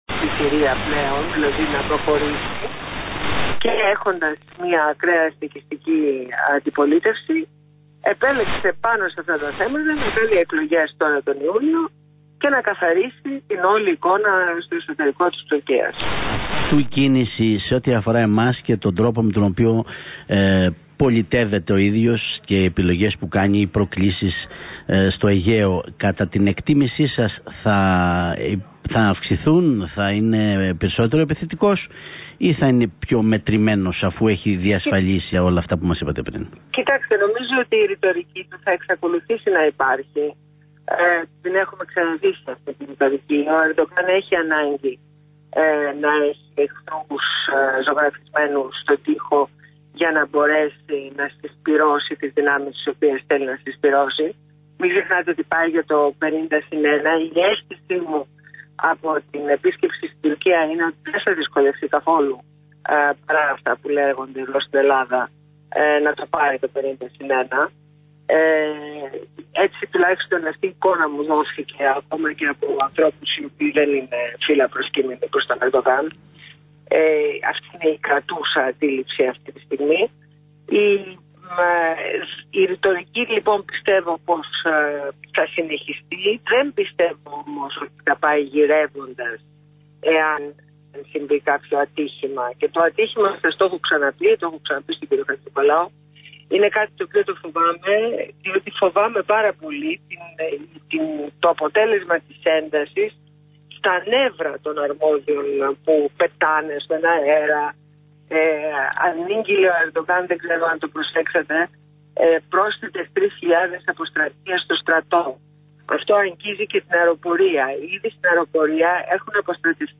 Συνέντευξη στο ραδιόφωνο του Realfm 97.8